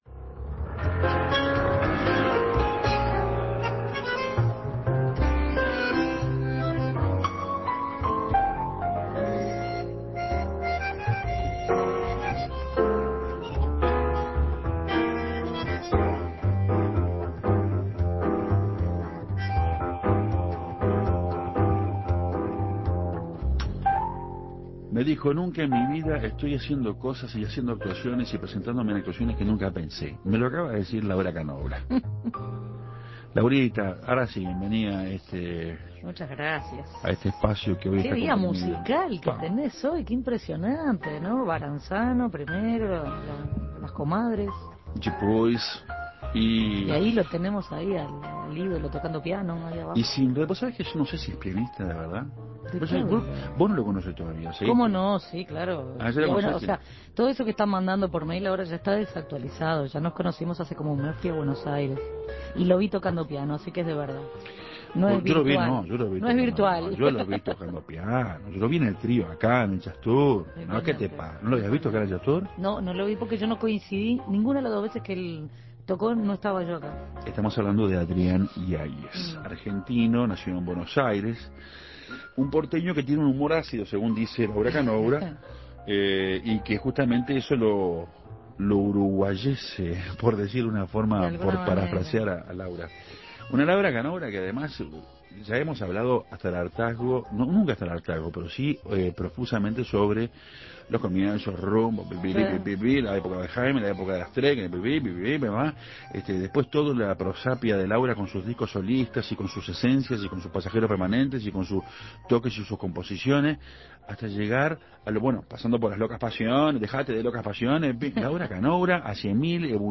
Juntos en el ciclo del Jazz Tour 2004 Canoura y Iaies. Laura Canoura desde estudios y Adrián Iaies desde Buenos Aires: entrevista, música, palabras en Café Torrado.